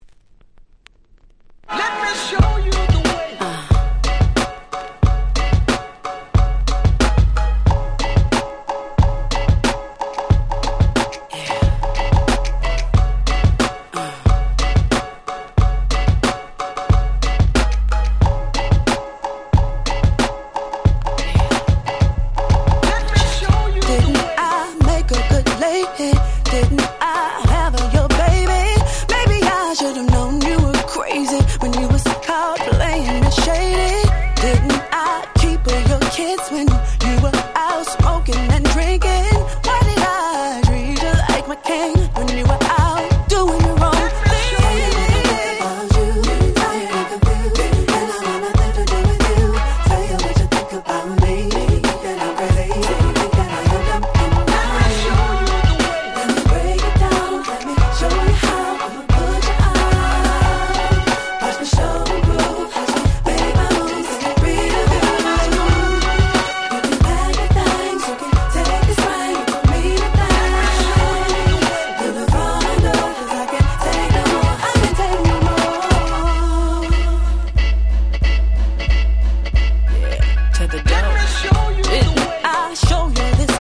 02' Smash Hit R&B Album !!